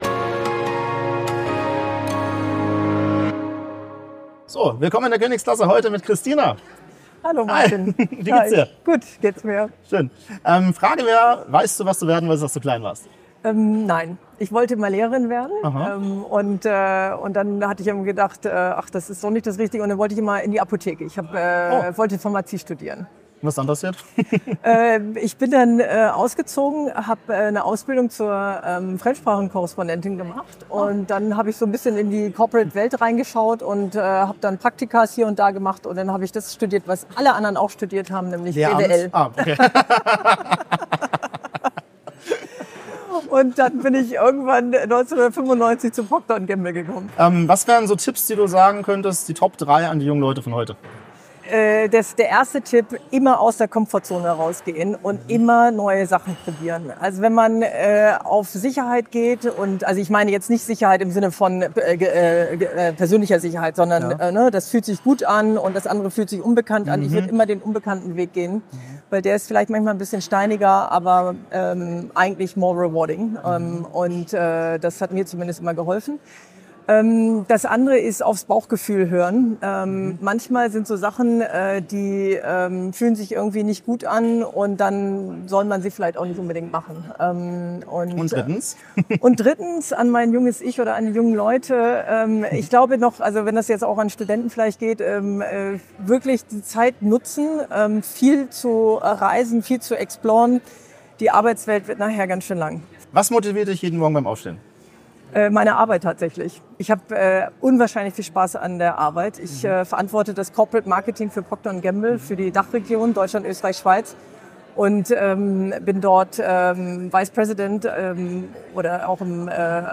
die Gen Z interviewen CXOs über ihr Leben